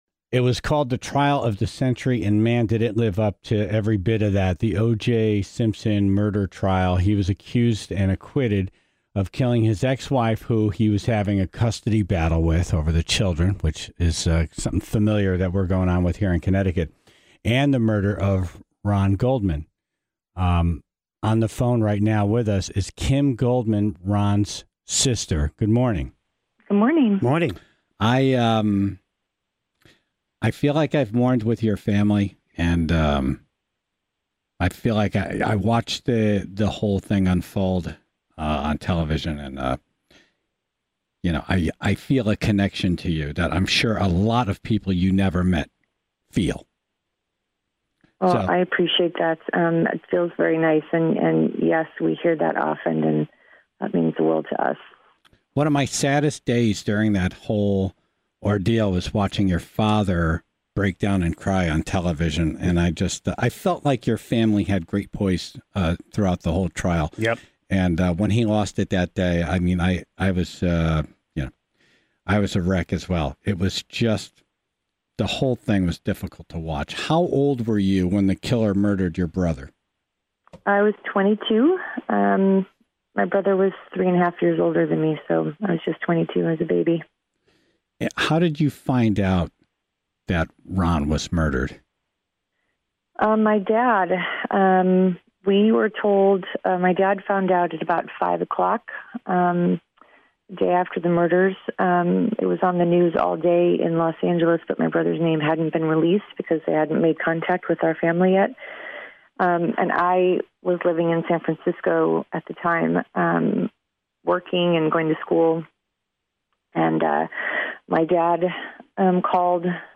the full, unedited interview